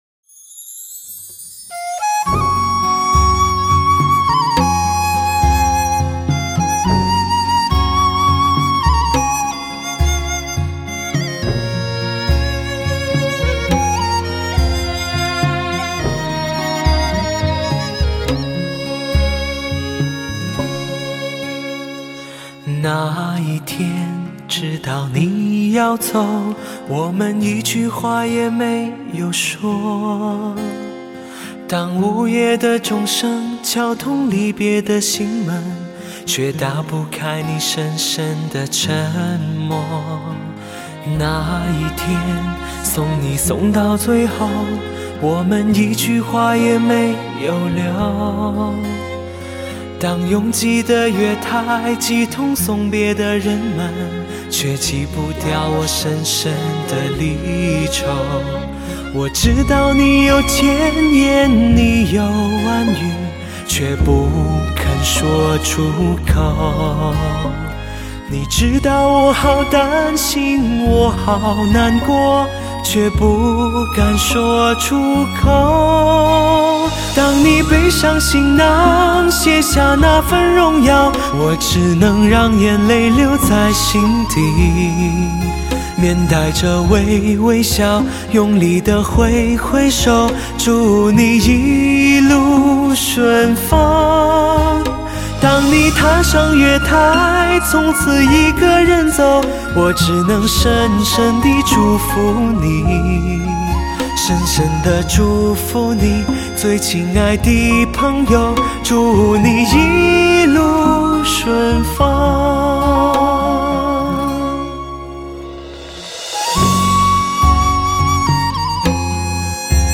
日本Memory-Tech株式会社专利母盘制作技术，高品位再现“音乐的空气感”。